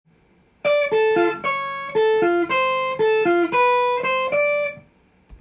譜面通り弾いてみました
③のフレーズは、１弦が半音ずつ下がっていく
クリシェと言われる手法を用いたフレーズです。